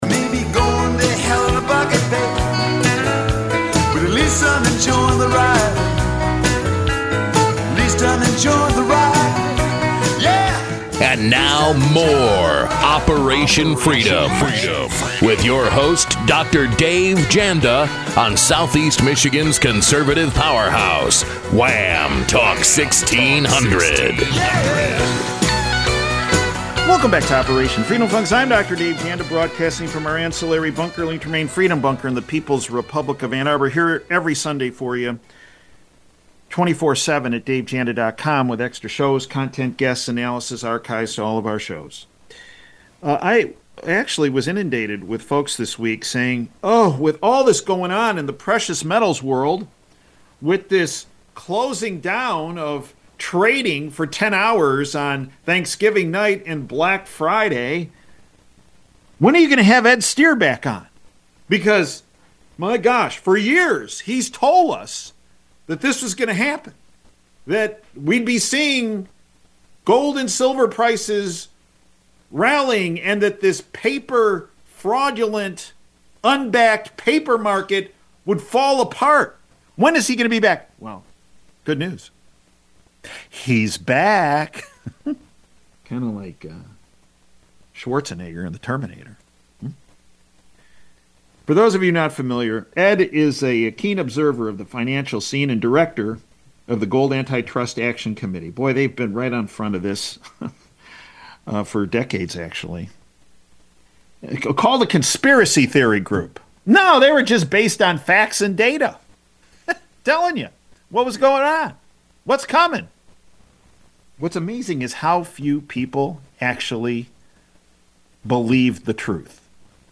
interviewed about the collapse of Western gold and silver price suppression policy